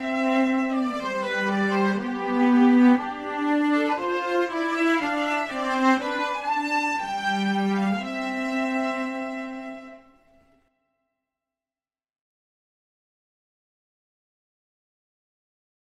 上のデモ音源の状態でもそれなりの質感はありますが、演奏の厚みやリバーブなどを調整することでより立体的なサウンドに仕立てることができます。
演奏に厚みや人数感を表現するのに役立つのが「アンサンブル」機能です。
今回は「Church」大部のリバーブを各トラックで選び、ビオラの方は20％、
チェロの方は10％に設定しました。